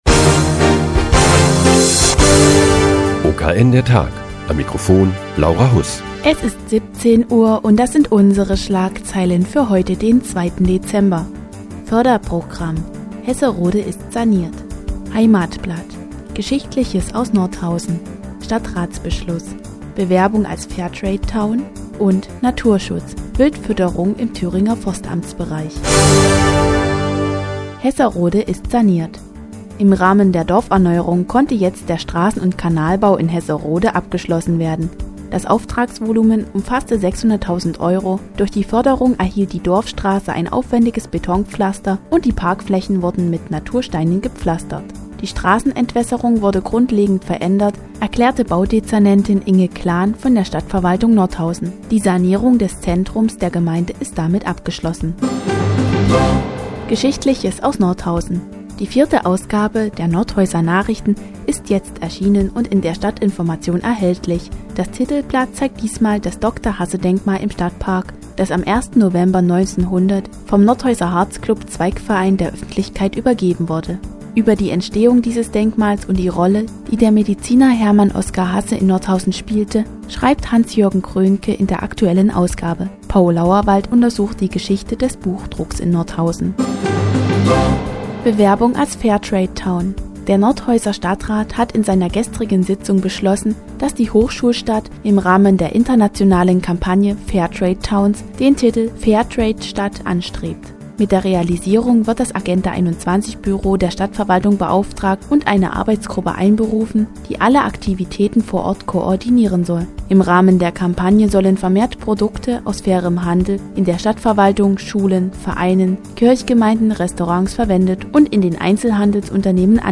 Die tägliche Nachrichtensendung des OKN ist nun auch in der nnz zu hören. Heute geht es um ein Nordhäuser Heimatblatt und die Wildfütterung im Thüringer Forstamtsbereich.